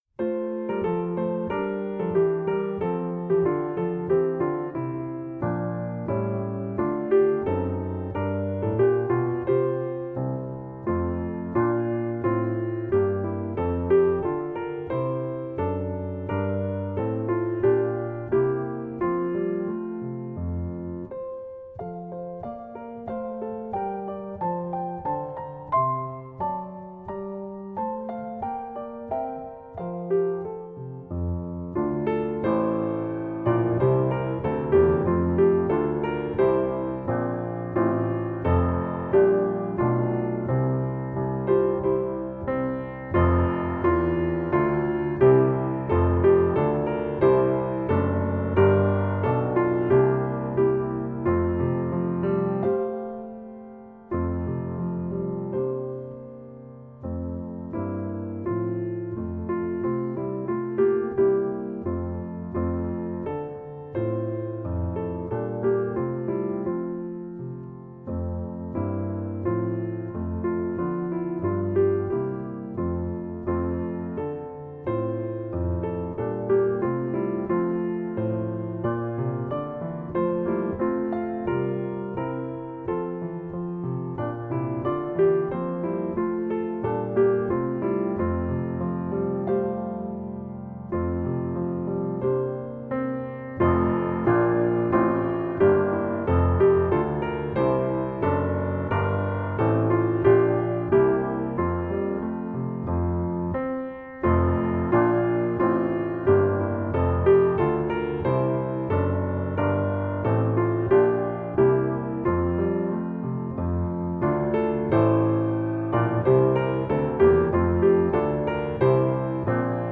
Voicing: Pno